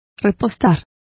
Complete with pronunciation of the translation of refuel.